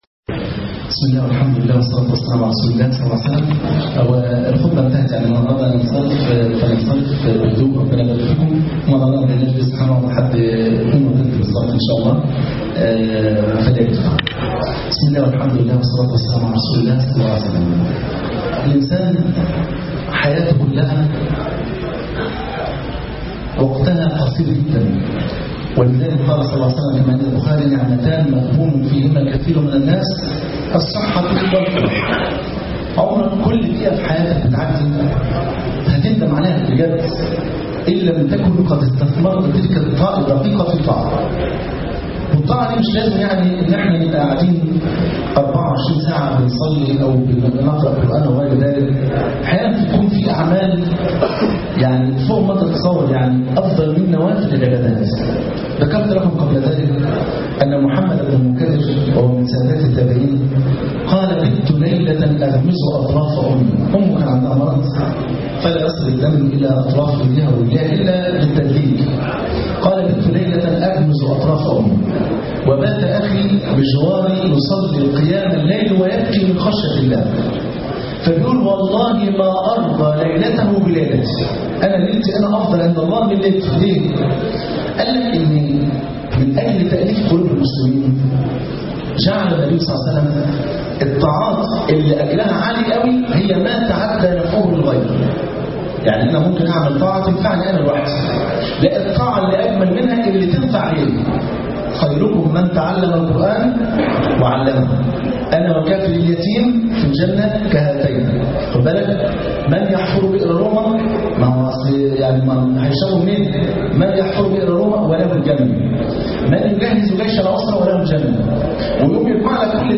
الطاعة ( 16/12/2014)درس في فرنسا